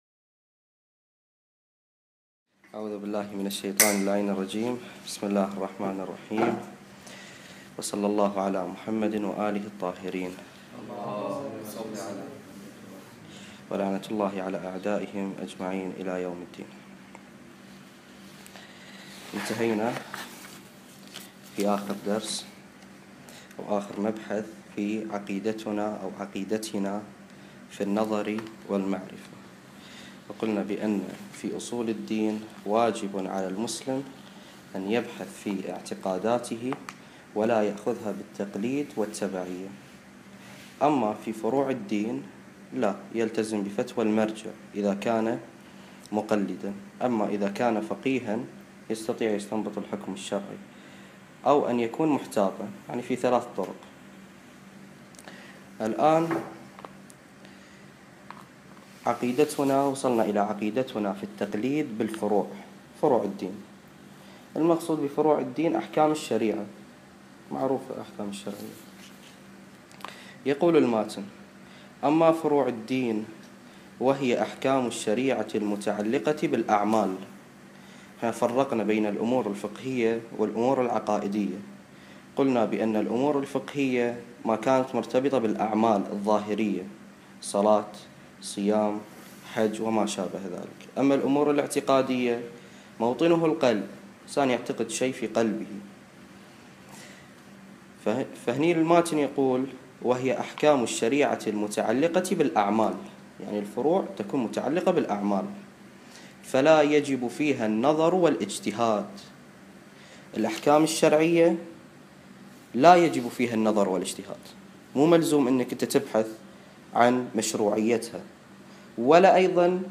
مكان التسجيل: هيئة اليد العليا، الكويت